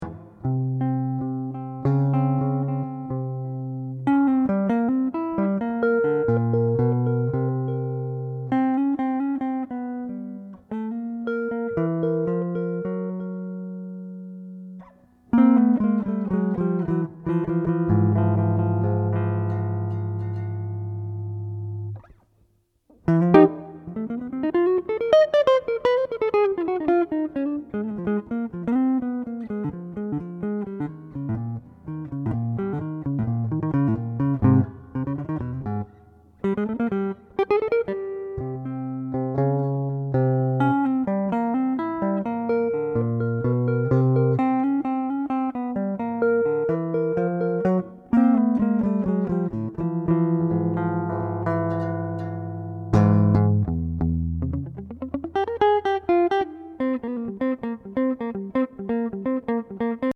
Solo CD